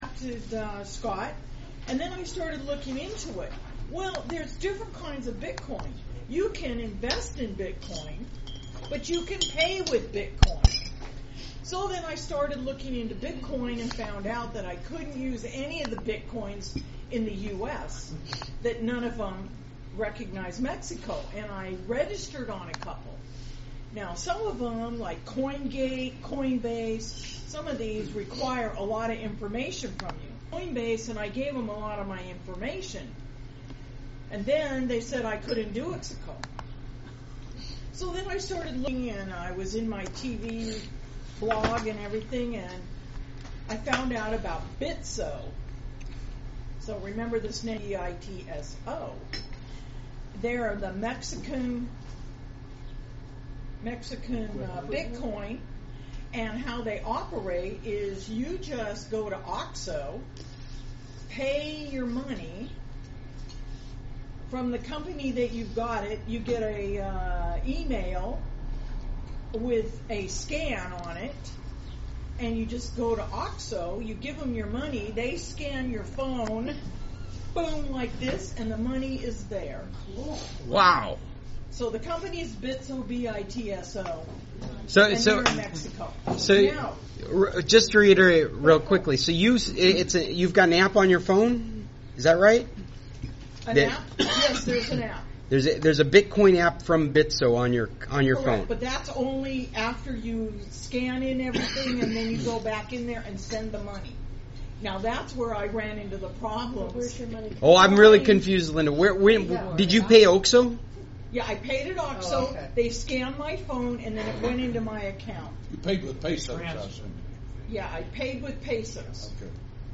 That was a huge meeting. 34+ members attended.